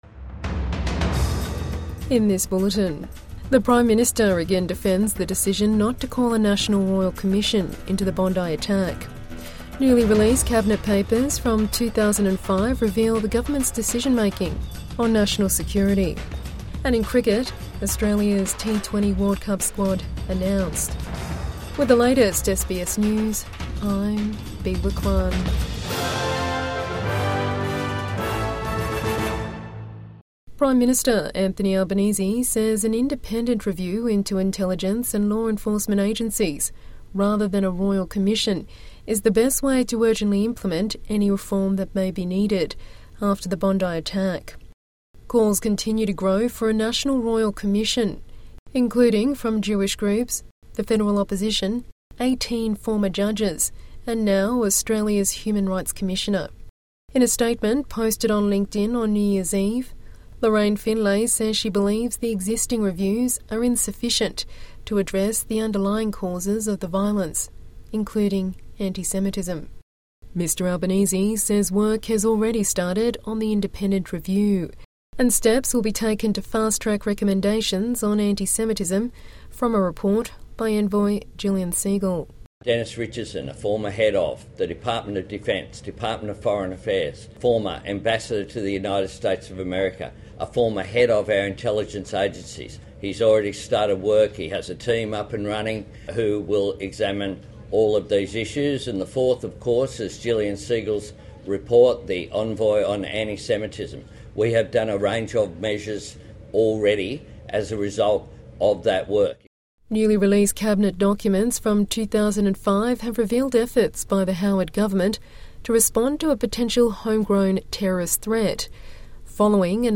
PM again defends decision on royal commission after Bondi attack | Evening News Bulletin 1 January 2026